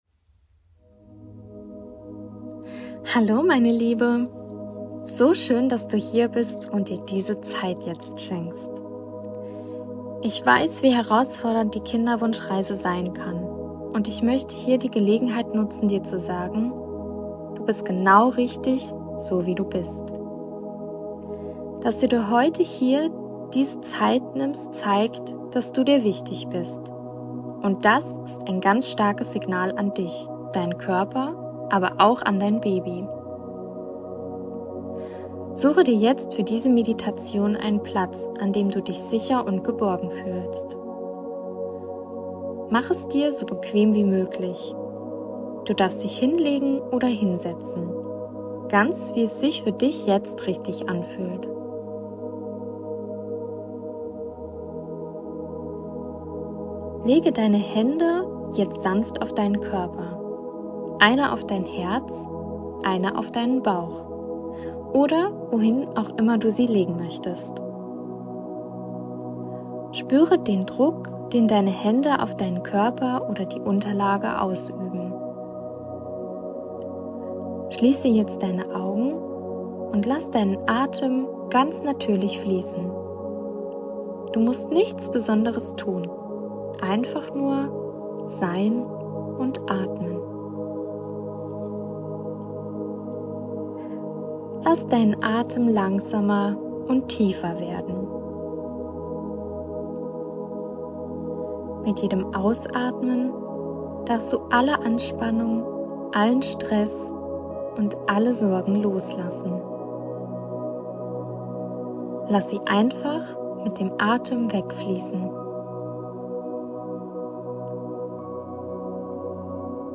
Kinderwunsch Meditation – Deine Doulabegleitung